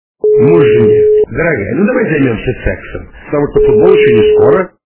» Звуки » Люди фразы » Муж жене - Давай займемся сексом
При прослушивании Муж жене - Давай займемся сексом качество понижено и присутствуют гудки.